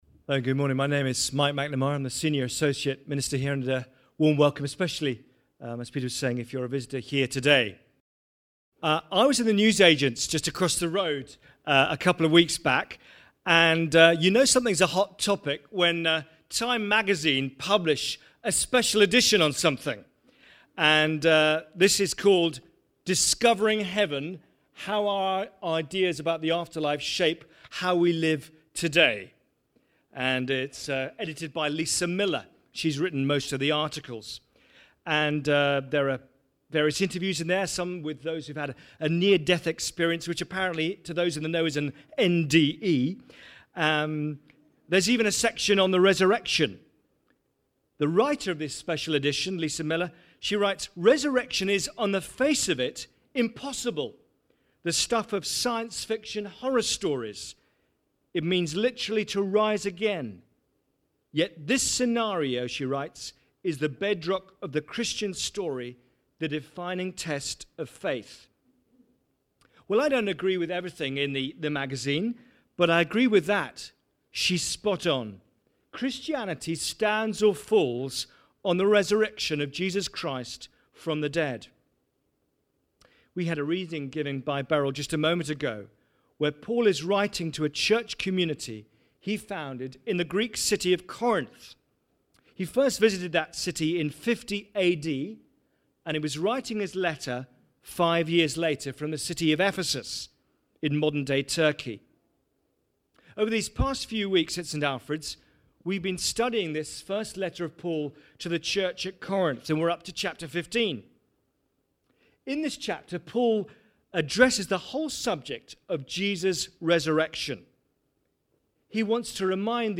Sermons | St Alfred's Anglican Church
Resurrection (10am)